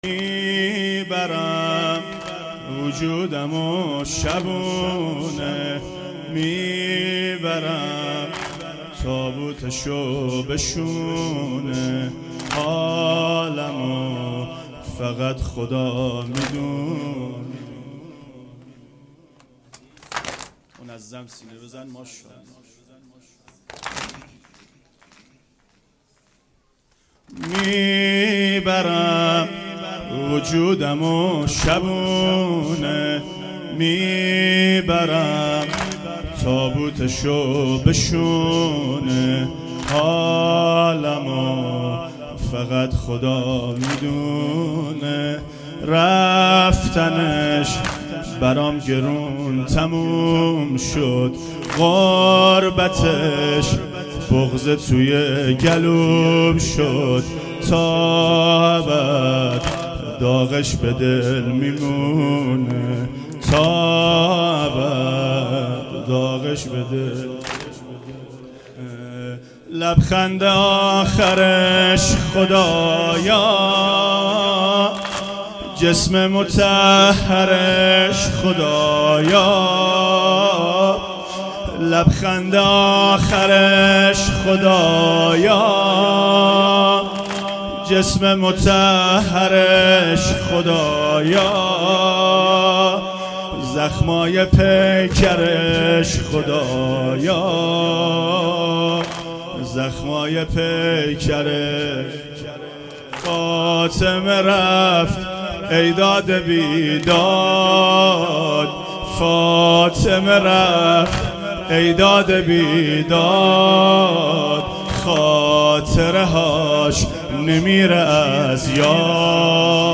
سنگین شب پنجم فاطمیه